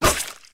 snd_enemymelee.ogg